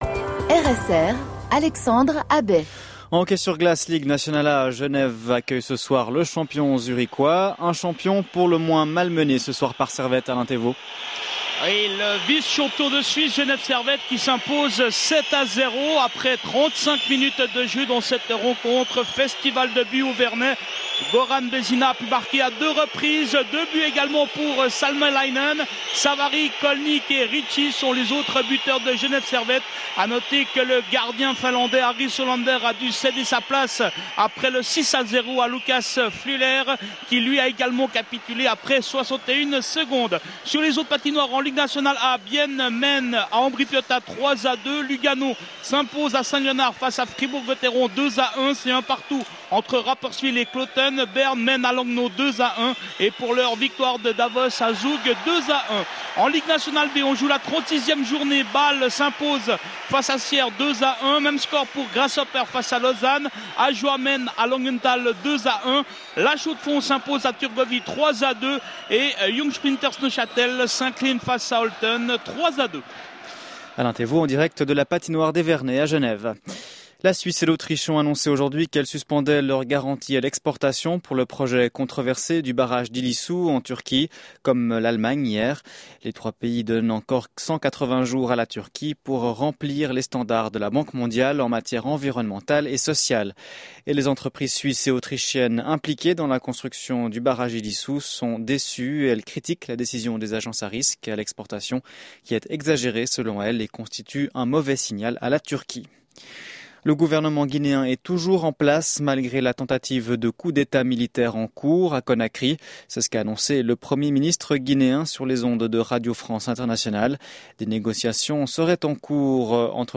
Le texte est tellement bien lu qu’on dirait même pas que c’est moi qui l’ai écrit.